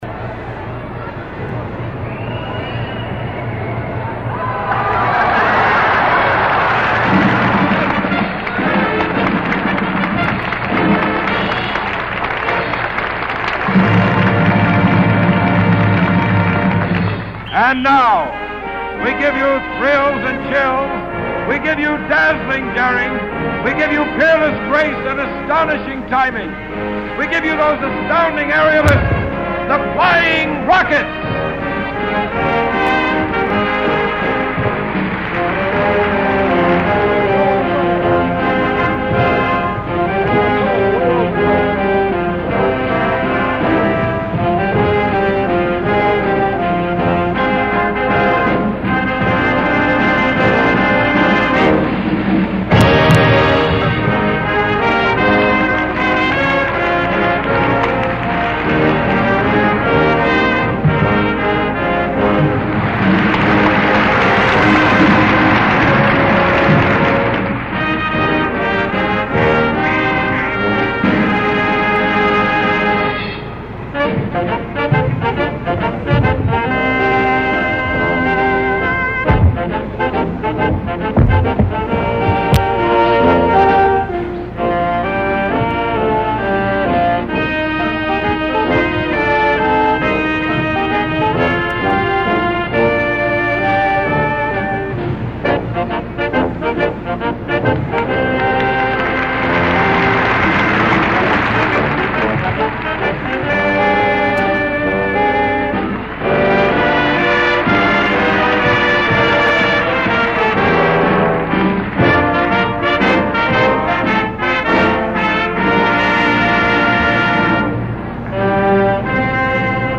CIRCUS MUSIC
drum roll